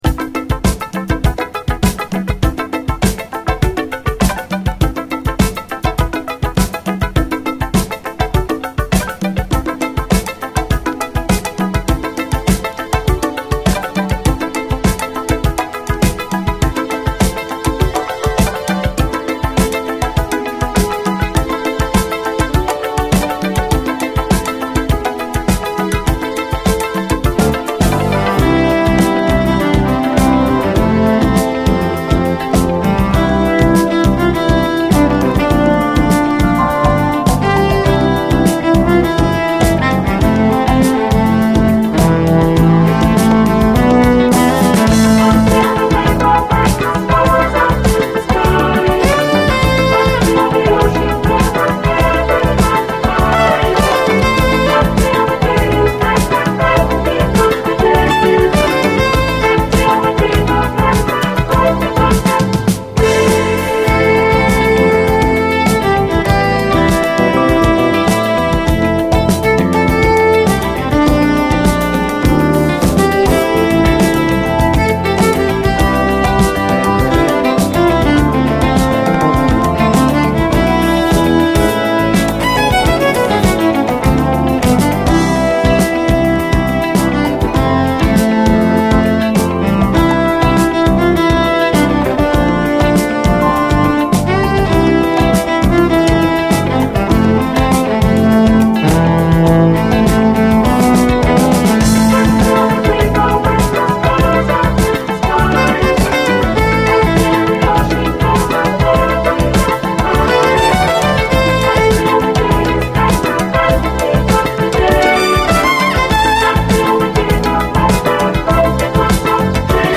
ファンキーに打ったビートとド派手ブラスが炸裂するファンキー＆グルーヴィー・ソウル満載！
ド迫力ブラスが炸裂するイントロの破壊力で一撃KOされるキラー・スウィート・ソウルです！